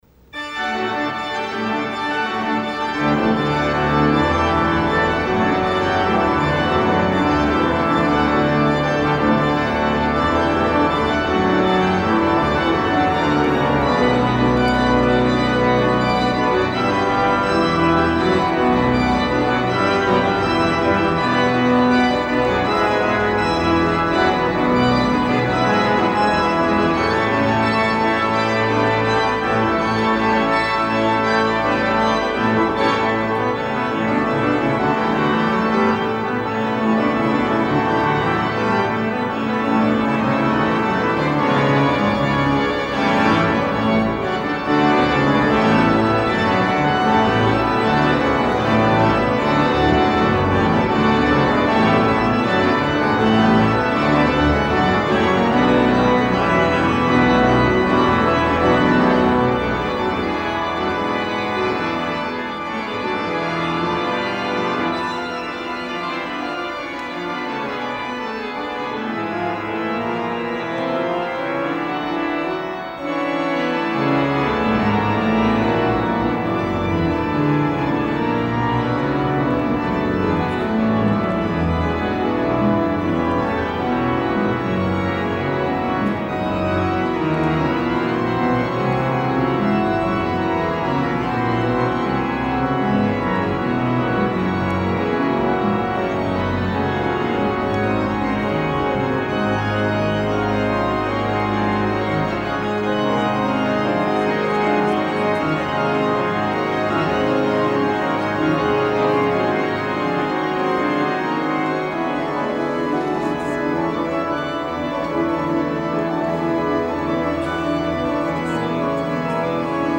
St. Paul's Cathedral, London, England